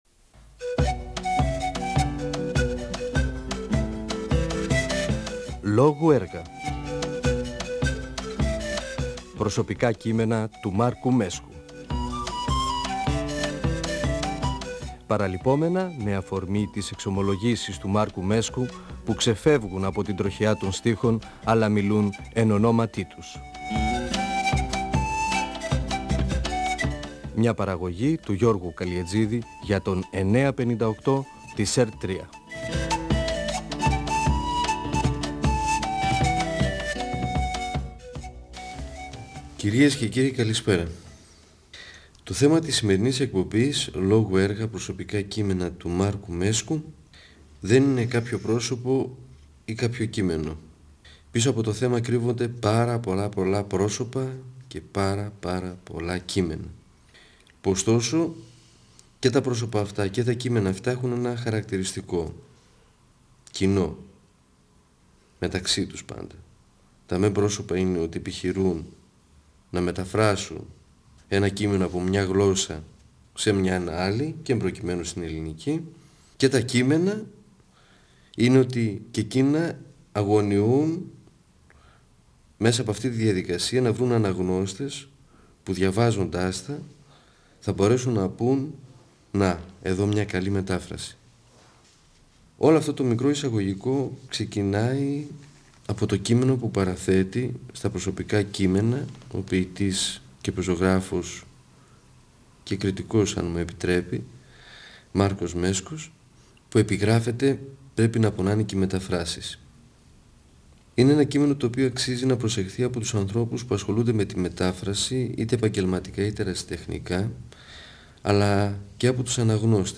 Ο ποιητής και δοκιμιογράφος Μάρκος Μέσκος (1935-2019) μιλά για τη σημασία και τις δυσκολίες τής μετάφρασης ενός λογοτεχνικού έργου (εκπομπή 1η).
Νεφέλη, 2000).ΦΩΝΕΣ ΑΡΧΕΙΟΥ του 958fm της ΕΡΤ3.